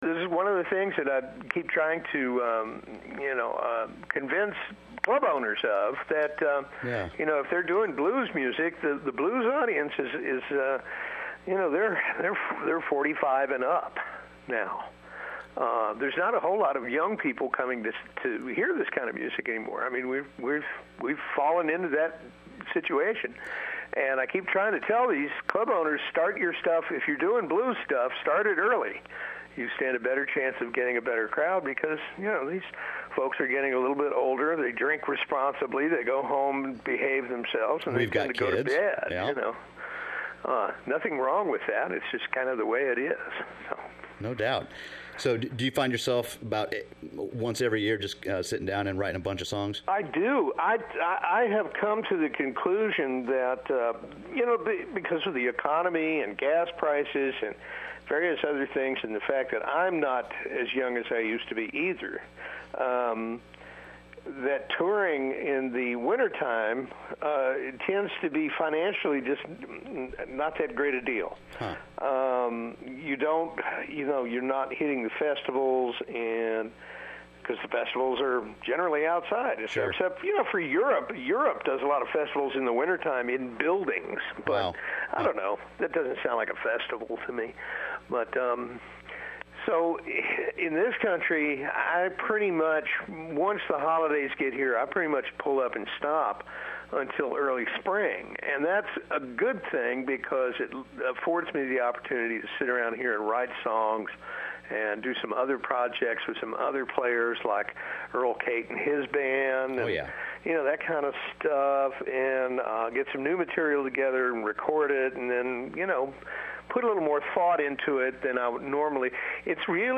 interviews blues singer and guitarist